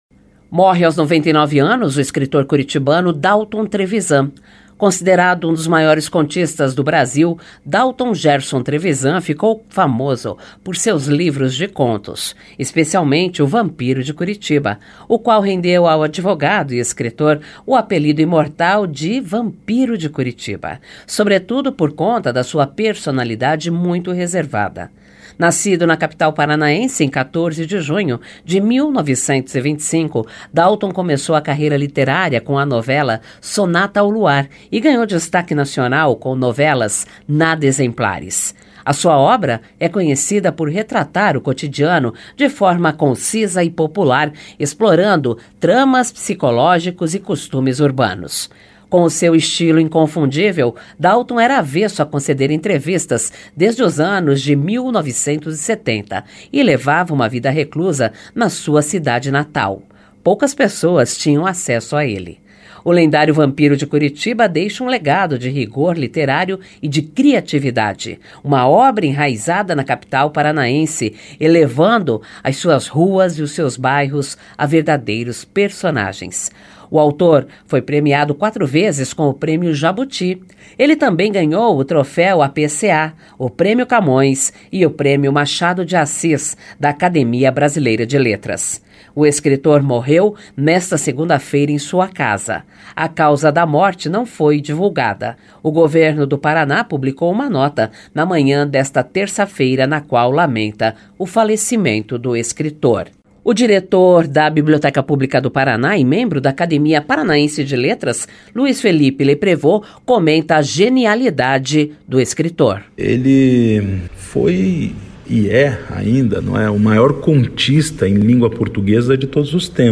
Nacional Informa: Boletim de notícias veiculado de hora em hora, com duração de três minutos.
* Este programete foi veiculado às 11h, ao vivo, nas rádios EBC.